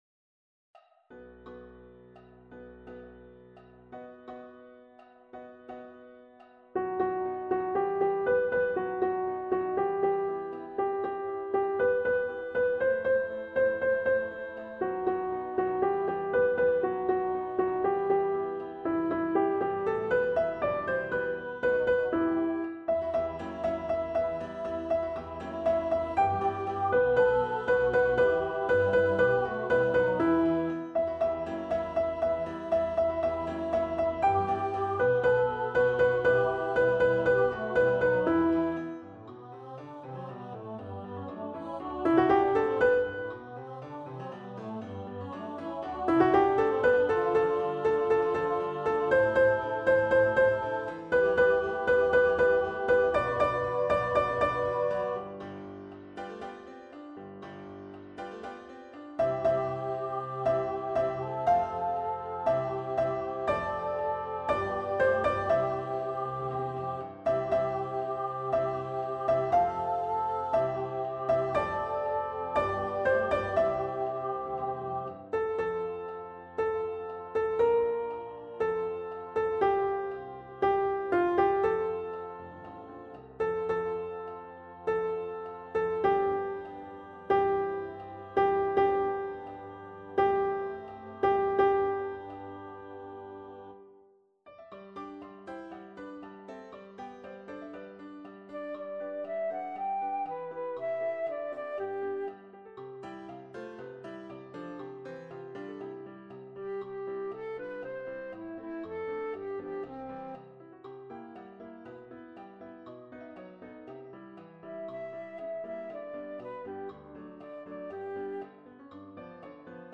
Schumann-Practice-Sop.mp3